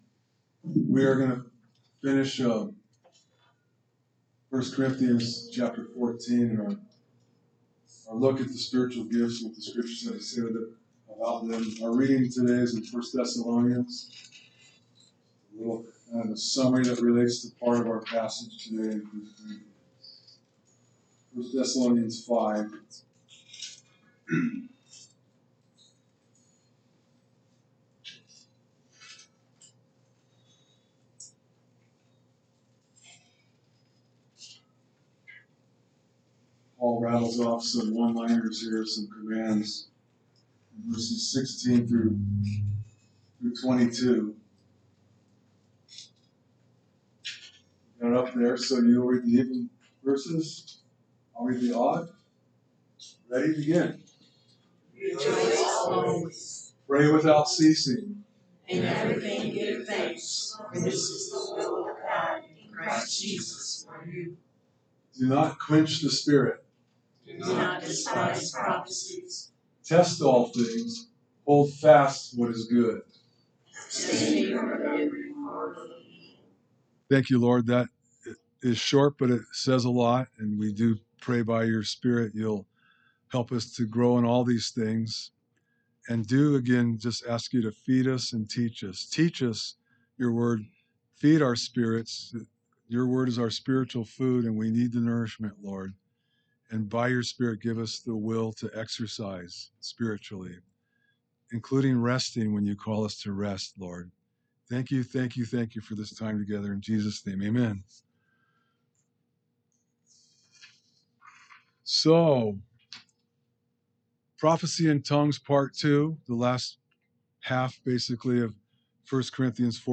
Sermons - Calvary Chapel Ames